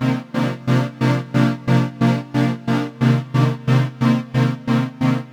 Index of /musicradar/sidechained-samples/90bpm